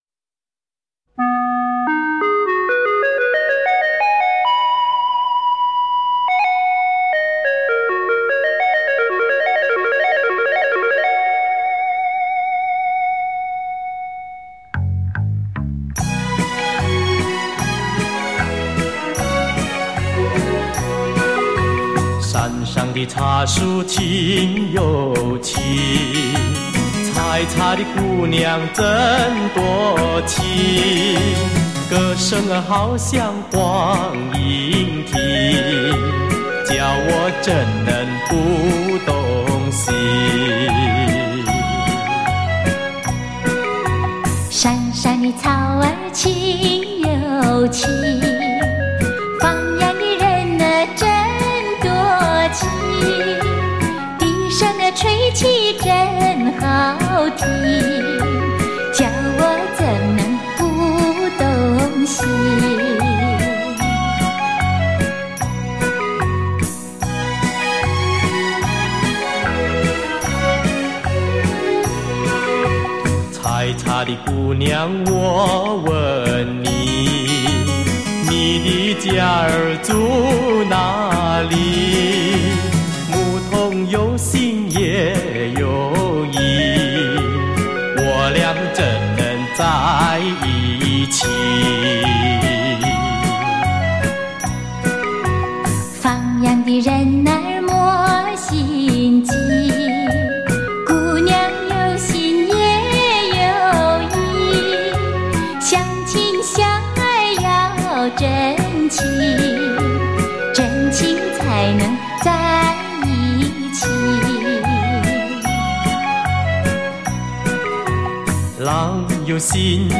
优美动听 歌喉悦耳 温心陶醉 经久不衰
曲子婉转细腻，带有轻巧的色彩，快板部分表现采茶姑娘的劳动景象，又表现了采茶姑娘们一同分享着劳动的快乐、丰收的喜悦 。